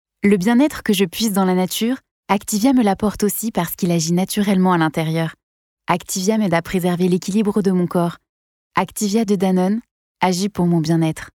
Sua entrega segura e acolhedora se adapta a comerciais, narrações e rádio, tornando-a uma escolha versátil para marcas que buscam serviços profissionais de locução.
Imagens de rádio
Microfone: Neumann TLM 103